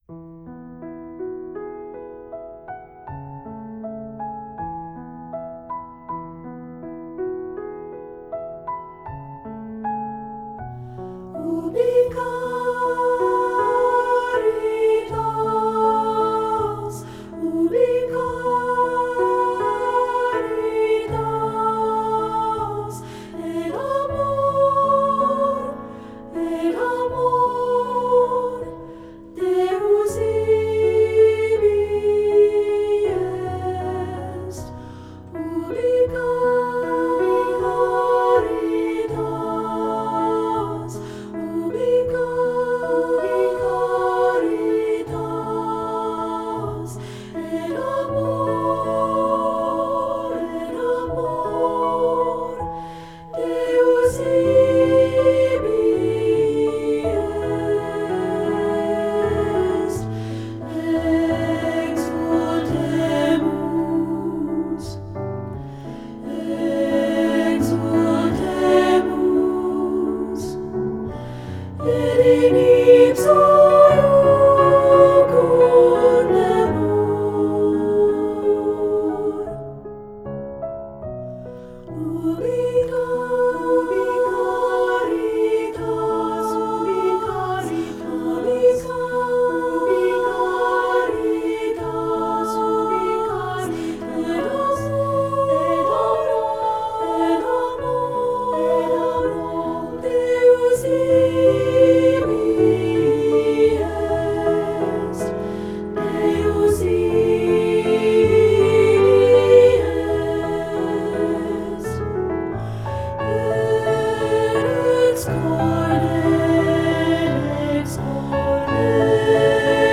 Voicing: Unison|2-Part and Piano